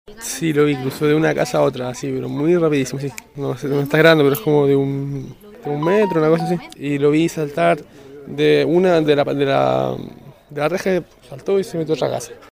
Uno de ellos relató el momento en que vio al animal.